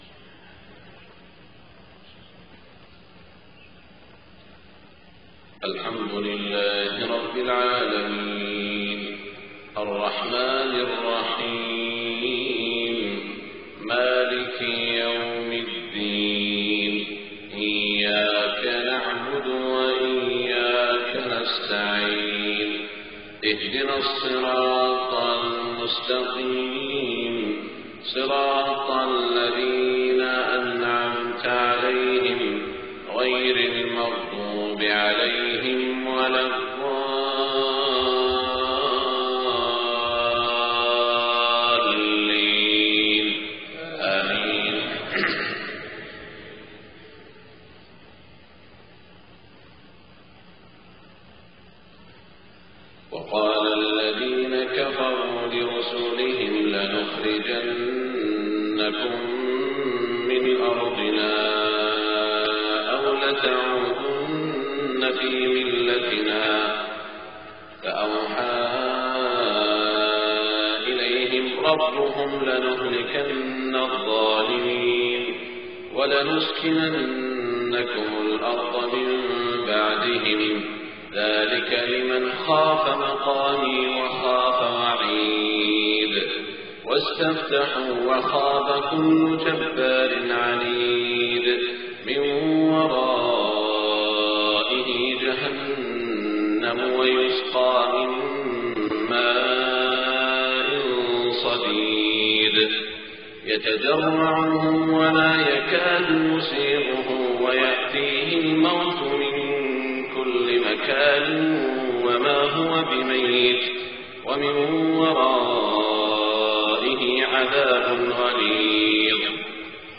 صلاة الفجر 7-6-1427 من سورة إبراهيم > 1427 🕋 > الفروض - تلاوات الحرمين